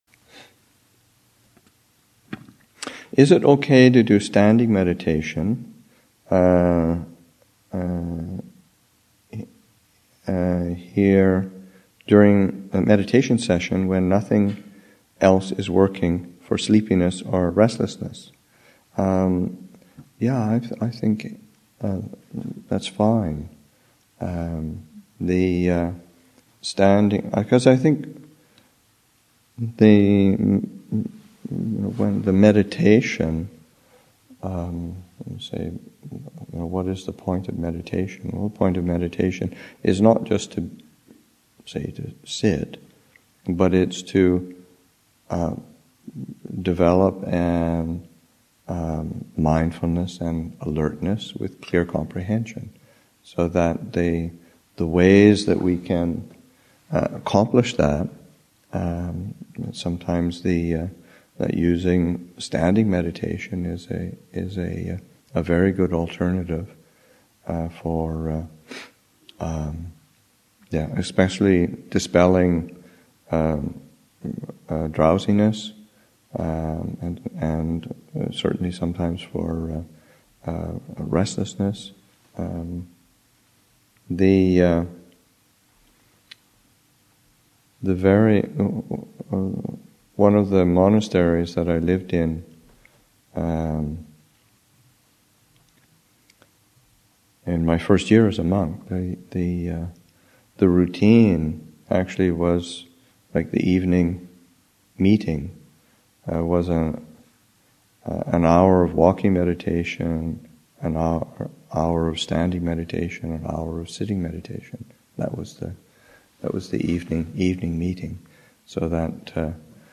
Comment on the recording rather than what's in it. Abhayagiri Monastic Retreat 2013, Session 6 – Nov. 28, 2013